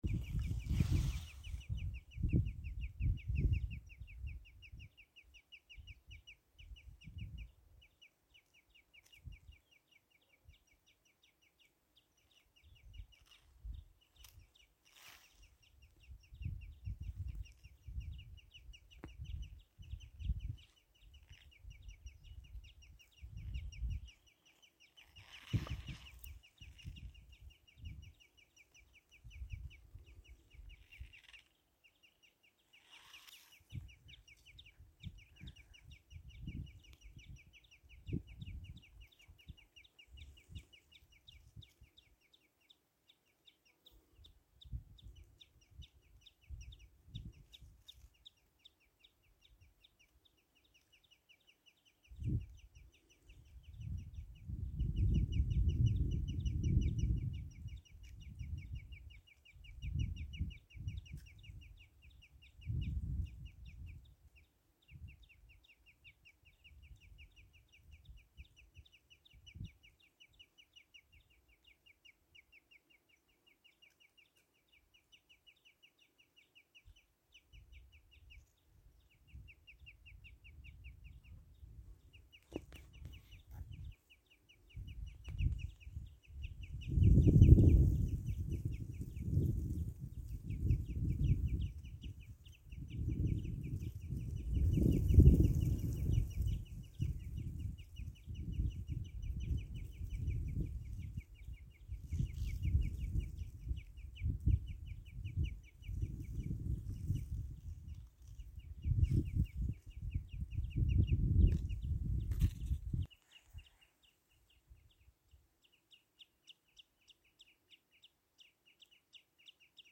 Lielā tilbīte, Tringa nebularia
StatussAizvilinoša vai uzbrūkoša uzvedība (AU)
PiezīmesIerakstā arī purva tilbītes uztraukuma sauciens